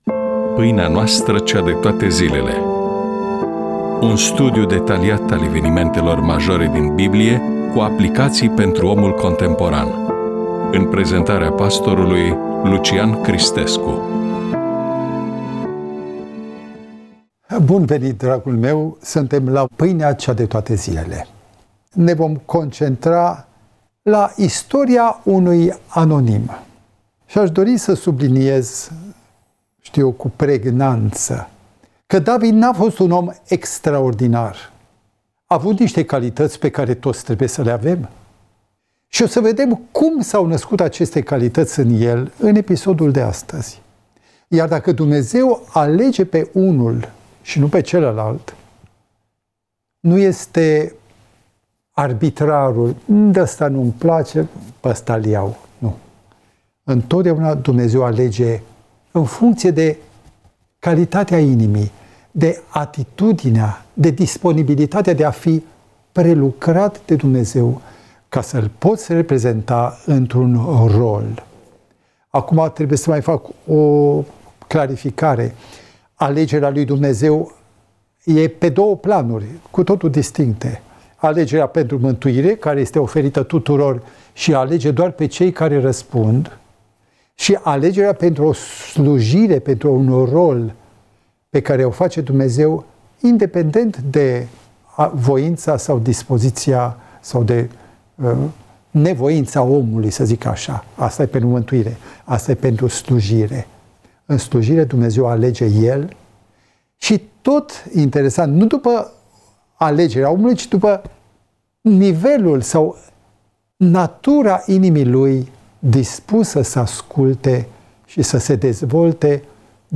EMISIUNEA: Predică DATA INREGISTRARII: 28.11.2024 VIZUALIZARI: 182